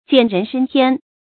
蹇人升天 jiǎn rén shēng tiān
蹇人升天发音
成语注音 ㄐㄧㄢˇ ㄖㄣˊ ㄕㄥ ㄊㄧㄢ